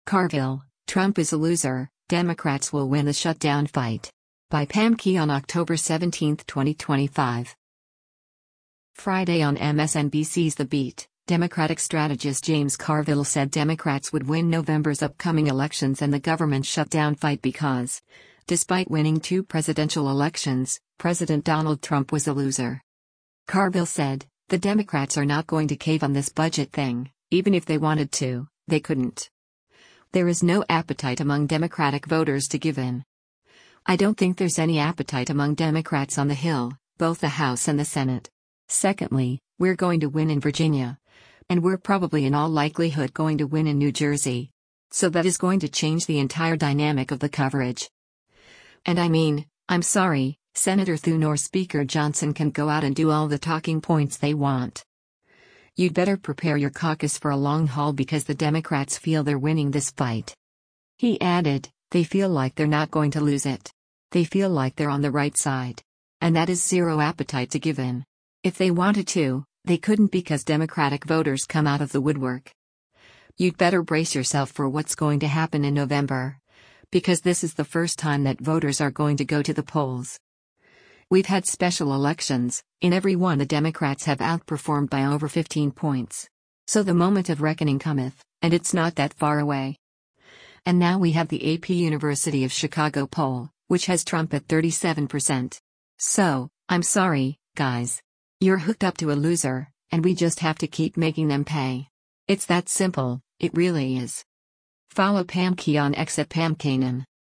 Friday on MSNBC’s “The Beat,” Democratic strategist James Carville said Democrats would win November’s upcoming elections and the government shutdown fight because, despite winning two presidential elections, President Donald Trump was a “loser.”